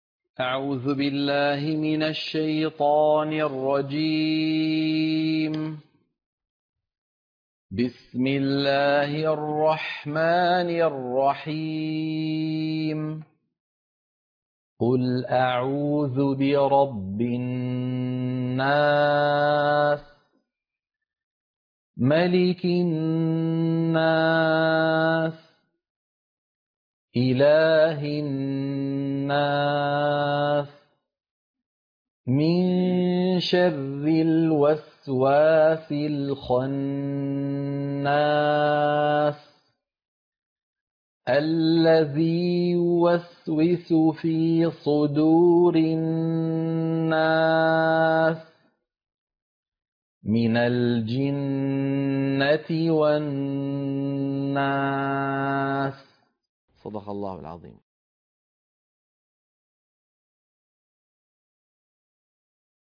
سورة الناس - القراءة المنهجية - الشيخ أيمن سويد